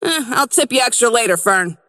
McGinnis voice line - Eh, I'll tip you extra later Fern.